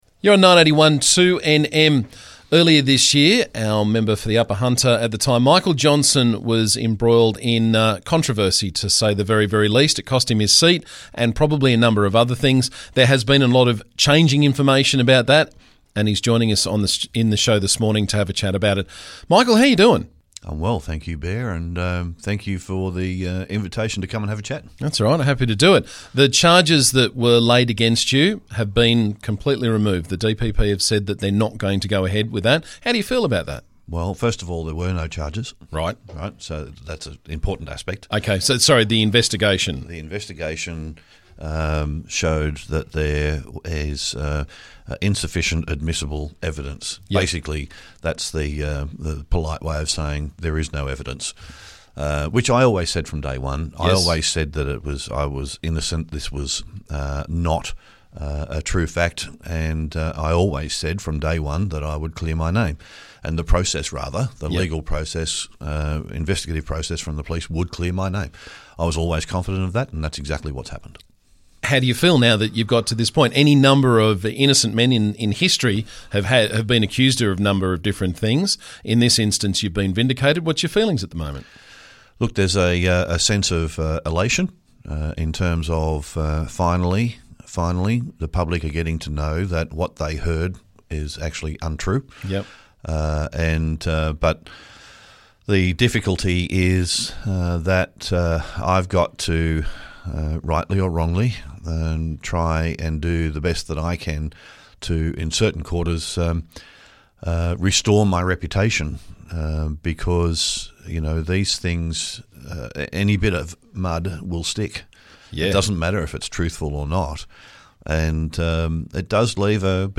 Former Member for Upper Hunter Michael Johnsen was on the show to talk about the recent investigation which cost him his seat in the NSW Parliament and his plans for the future.